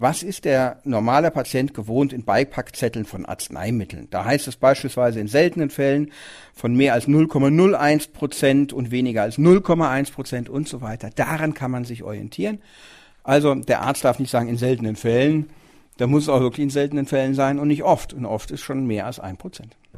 O-Ton: Auch Heilpraktiker müssen für falsche Behandlungen gerade stehen